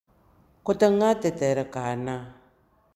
Lecture et prononciation